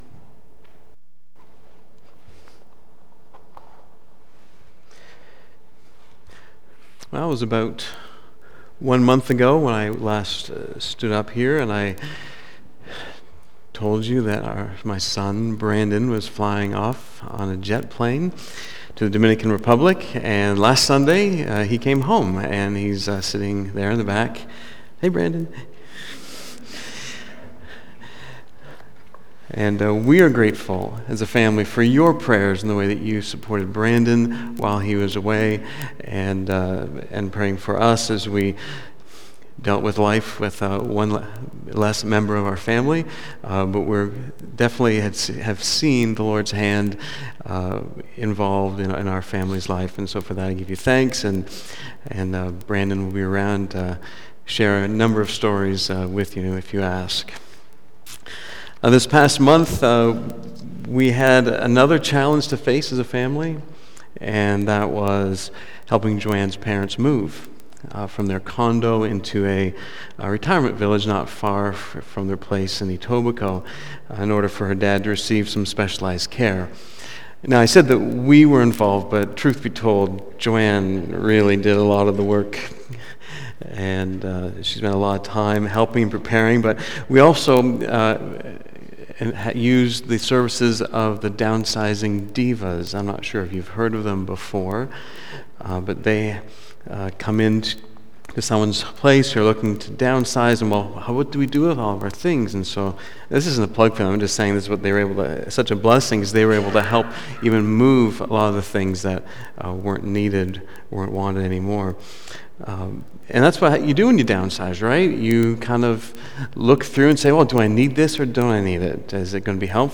2015 Prepare Him Room BACK TO SERMON LIST Preacher